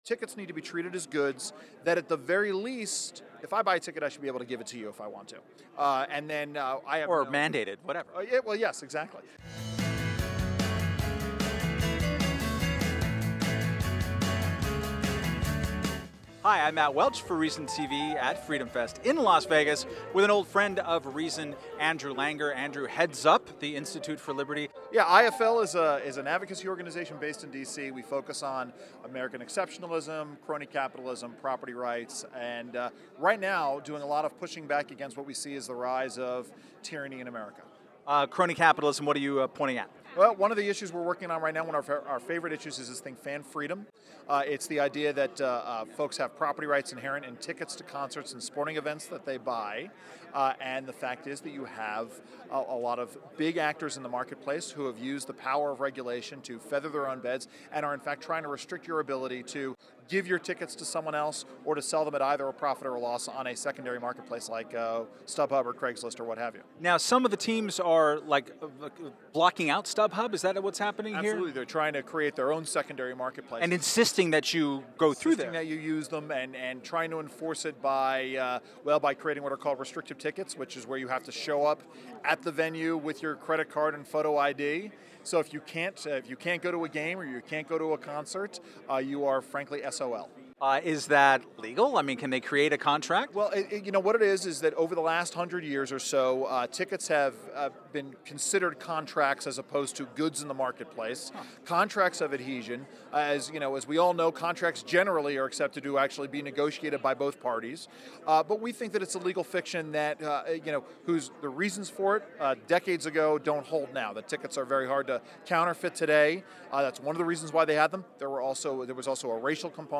Held each July in Las Vegas, Freedom Fest is attended by around 2,000 limited-government enthusiasts and libertarians. Reason TV spoke with over two dozen speakers and attendees and will be releasing interviews over the coming weeks.